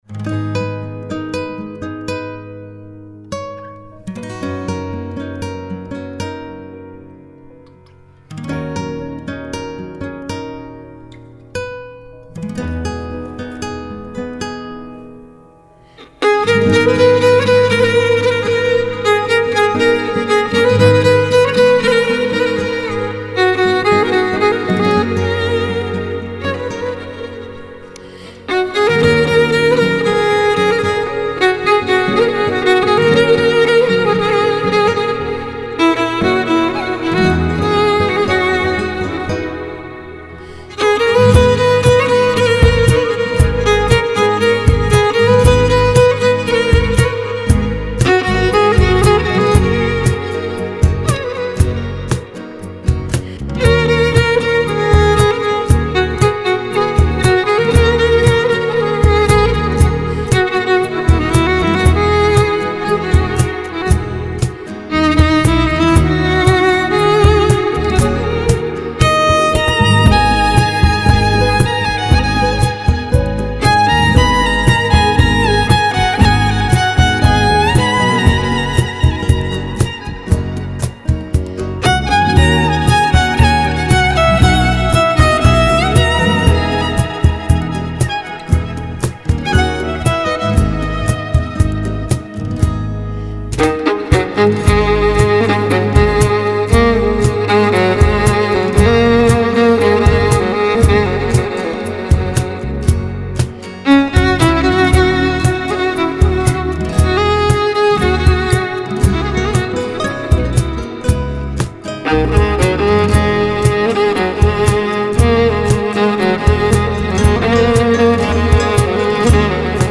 موزیک های بی کلام